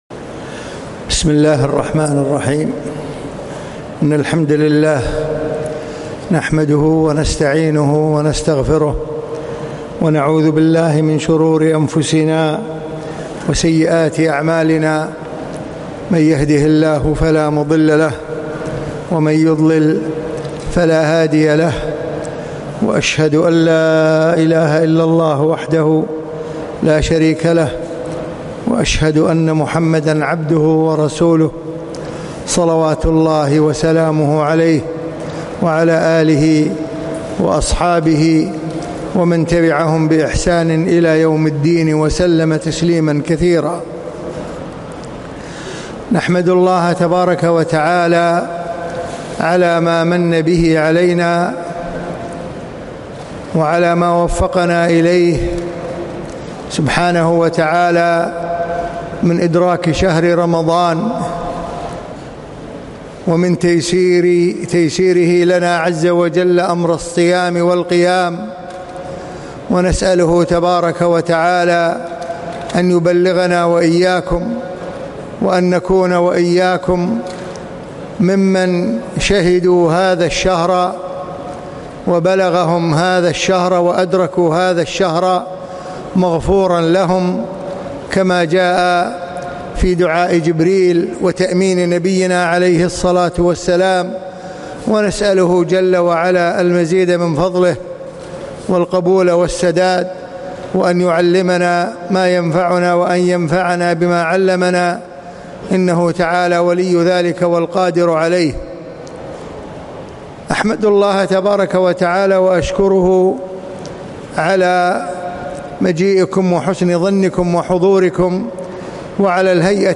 من دروس الشيخ في دولة الإمارات لعام 1439
السابقون السابقون - محاضرة